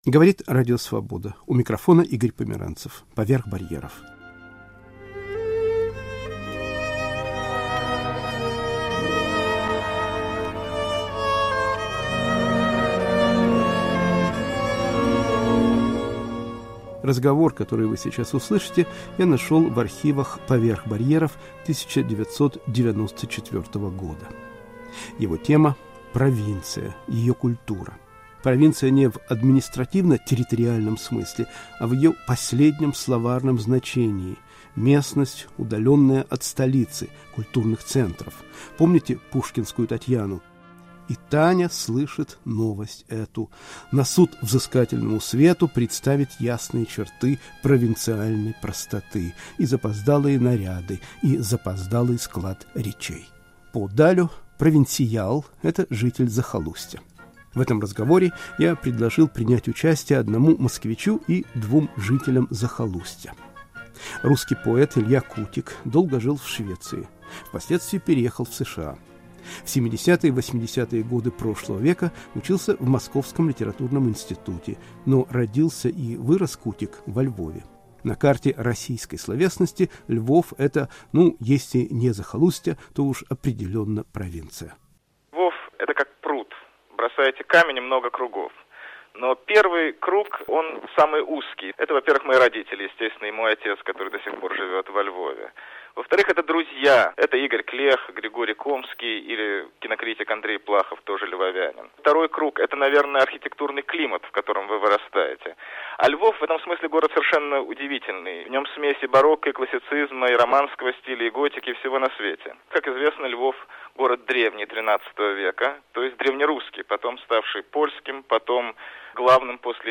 В передаче звучит голос филолога Сергея Аверинцева (1937-2004)